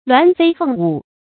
鸞飛鳳舞 注音： ㄌㄨㄢˊ ㄈㄟ ㄈㄥˋ ㄨˇ 讀音讀法： 意思解釋： 形容祥瑞和平的環境。